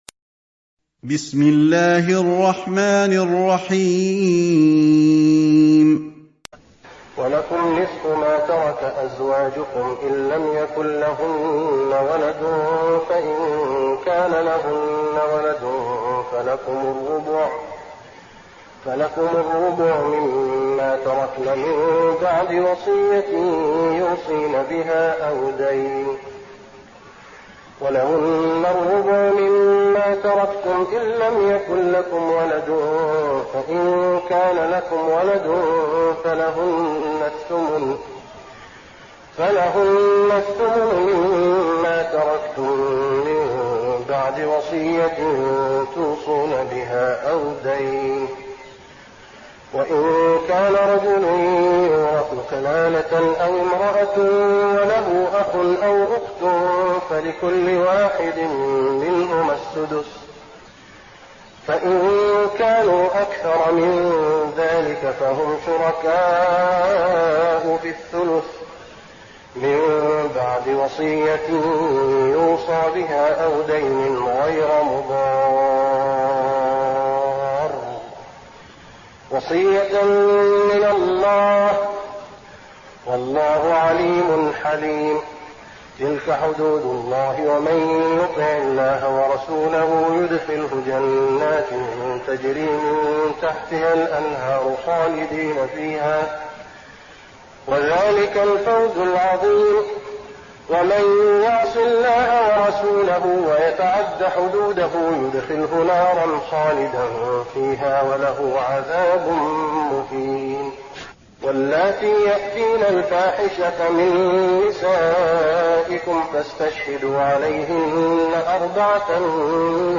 تهجد رمضان 1415هـ من سورة النساء (12-70) Tahajjud Ramadan 1415H from Surah An-Nisaa > تراويح الحرم النبوي عام 1415 🕌 > التراويح - تلاوات الحرمين